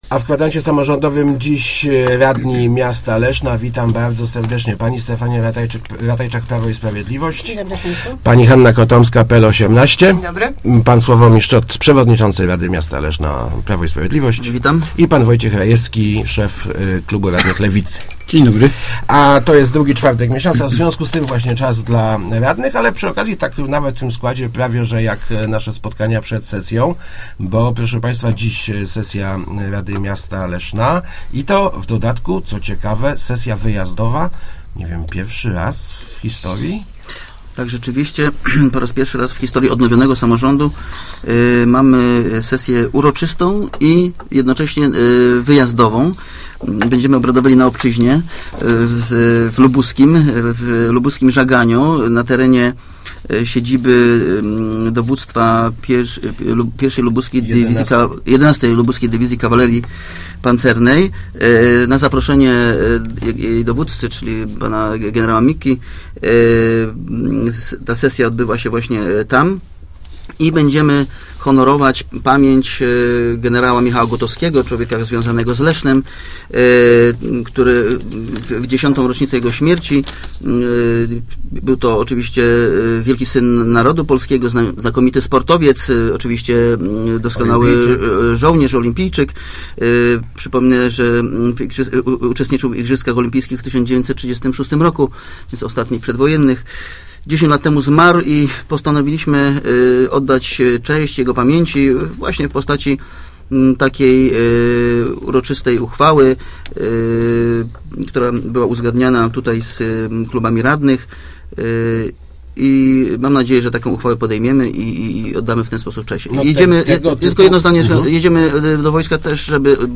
Odpowiedzi udzielali radni podczas ostatniego Kwadransa Samorz�dowego.